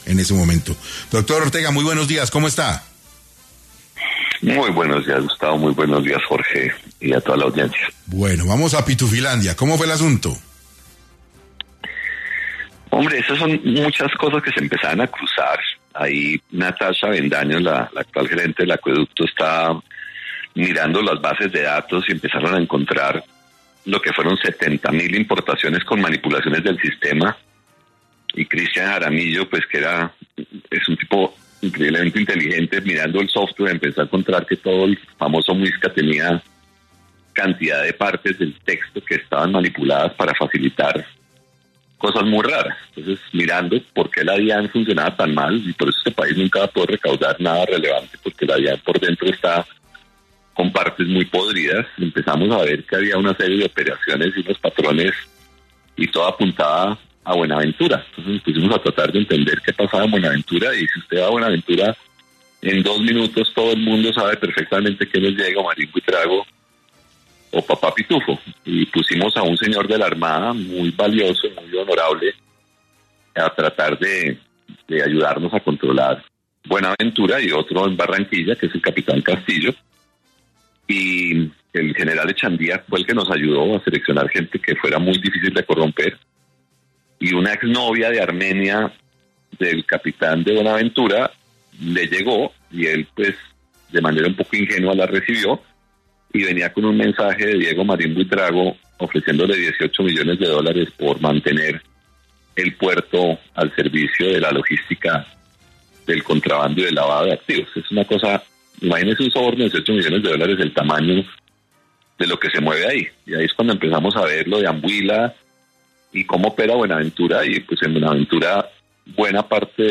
En entrevista con 6AM de Caracol Radio, Juan Ricardo Ortega, exdirector de la DIAN, dio detalles de la estrategia que puso en marcha la entidad para rastrear las operaciones del contrabandista en Colombia, al igual que sus alianzas en el mundo.